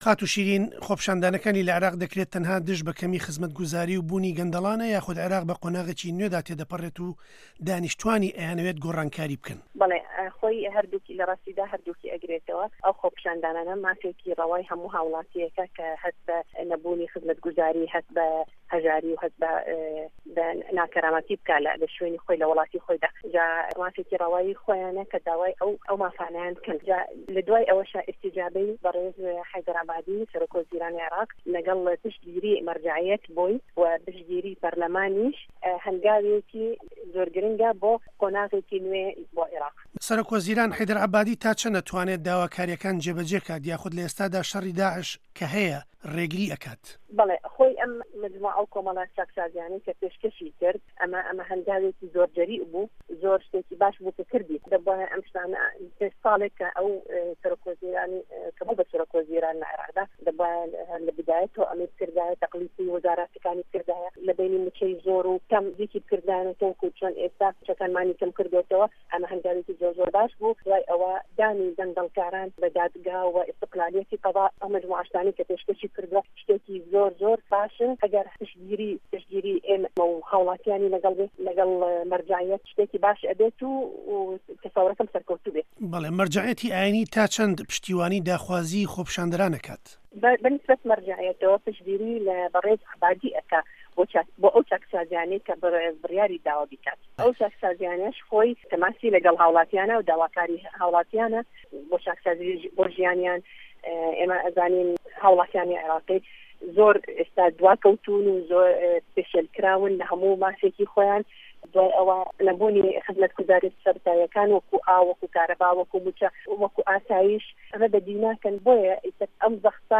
ئه‌مه‌ ته‌وه‌ره‌ی گفتووگۆی ده‌نگی ئه‌مریکایه‌ له‌گه‌ڵ خاتوو شیرین ره‌زا ئه‌ندامی په‌رله‌مانی عێراق.
گفتووگۆ له‌گه‌ڵ شیرین ڕه‌زا